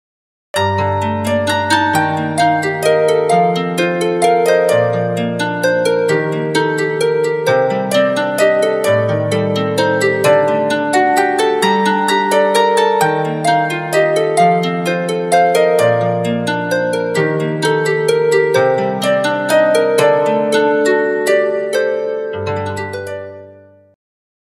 19. harp vox